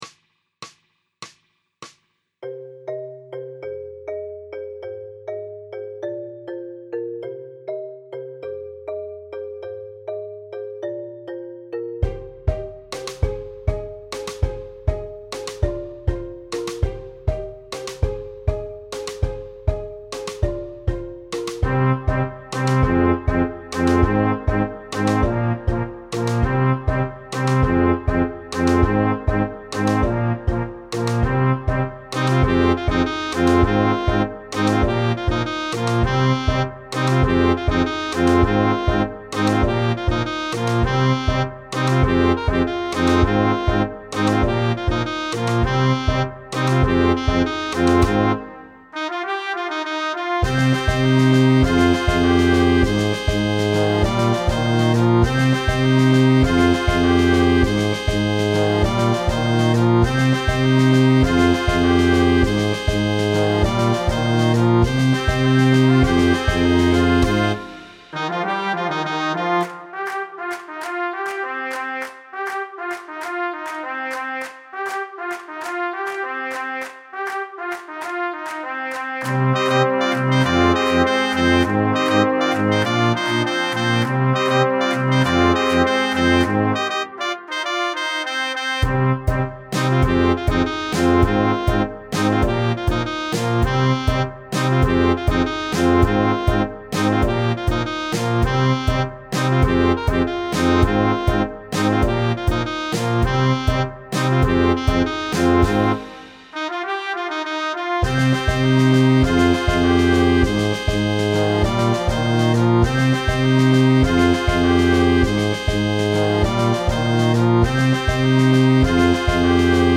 Style – Tempo – Signature – Durée : Pop – 192 – 4/4 –
Alto Saxophone 1
Trompette Bb 1
Trombone 1
Tuba
Batterie
Marimba
ECOUTEZ LA VERSION MIDI DU MORCEAU